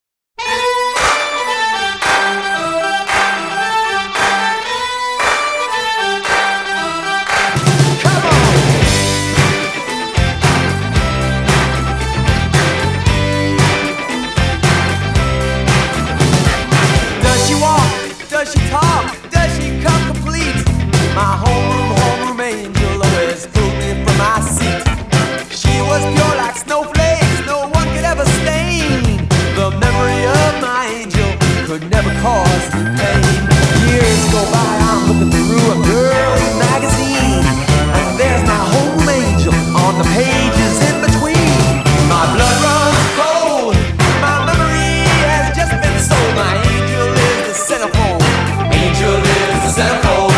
Tonart: F-Dur
für Solo Gesang und Blasorchester
Besetzung: Blasorchester